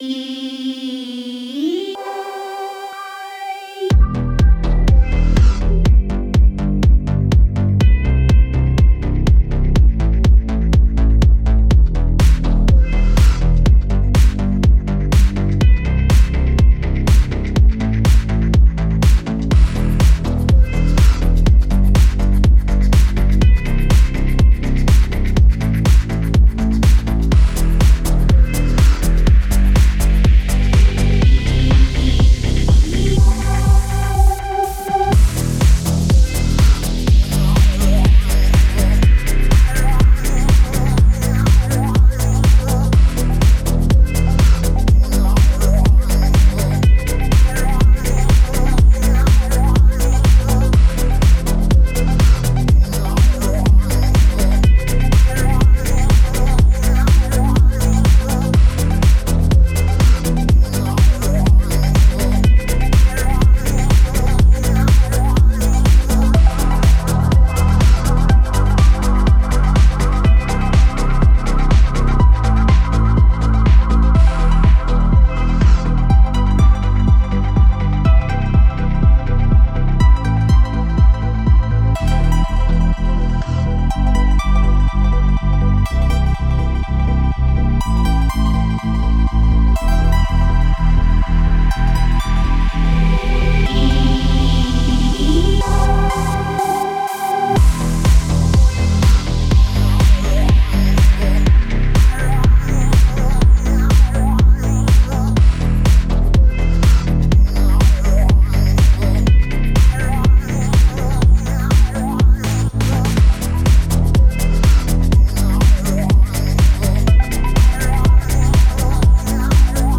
Pop/House/Trance (смесь :))